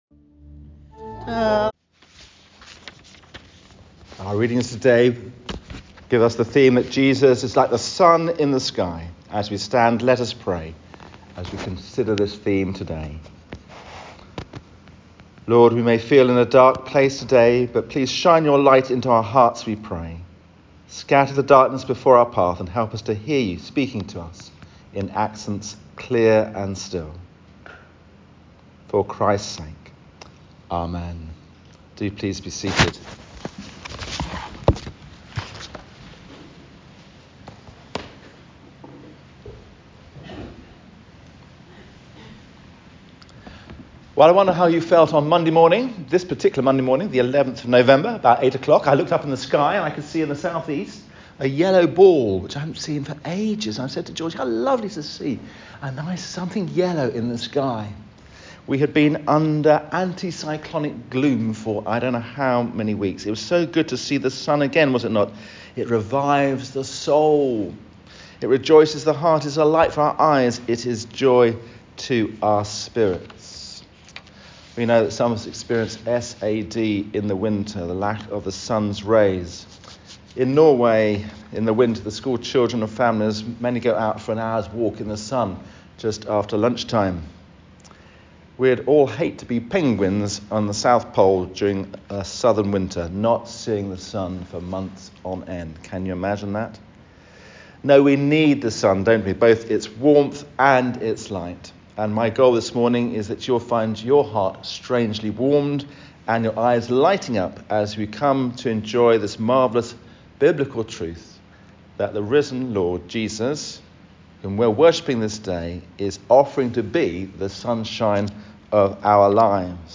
If you’d like something heart-warming to cheer you up on these dark November days, then it’s possible last Sunday’s sermon might do the trick.